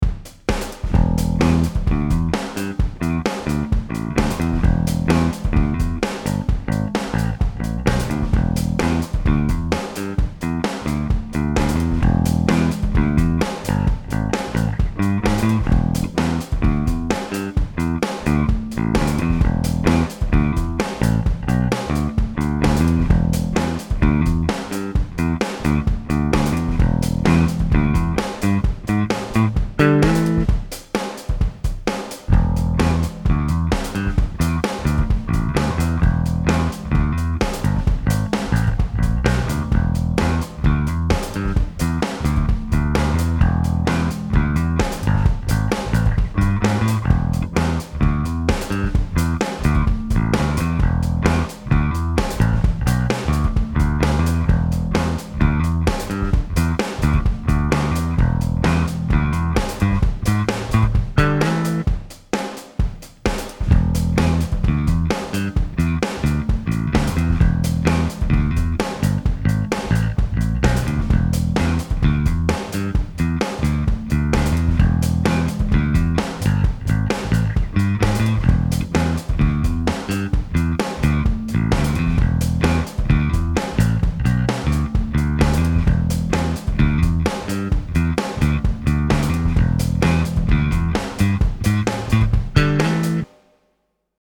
der Bassbereich geht in den Pumpernickel, also einen Kompressor.
der "alles" Bereich geht erst mal in den Bass OD also eine zerre und von da aus weiter in den Grafik EQ.
erster durchlauf ist das reine DI Signal des Basses, der zweite dann direkt nach dem VONG abgenommen und der dritte durchlauf ist nach dem vong dann in den peavey alphabass preamp.
jeweils ca. ne halbe Minute lang.